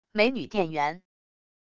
美女店员wav音频